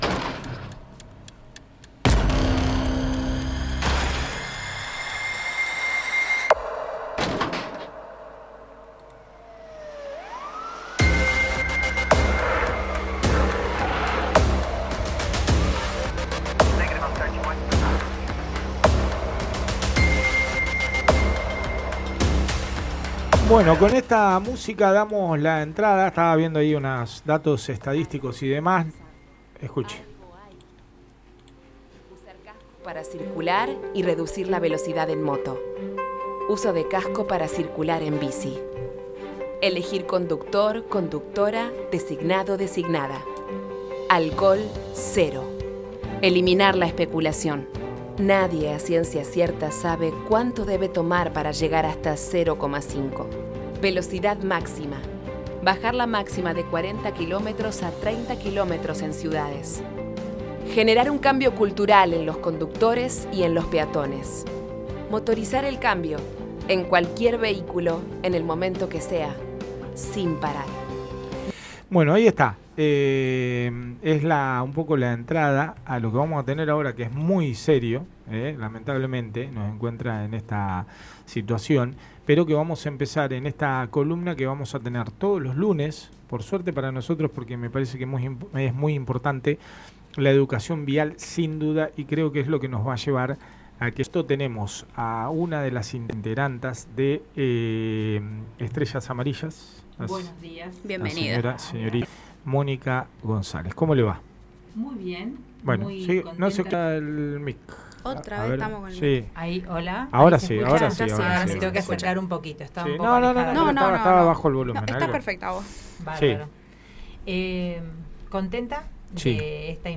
Charla con una de las integrantes del colectivo estrellas amarillas. Donde nos cuentan cuales fueron sus objetivos a lo largo de estos años.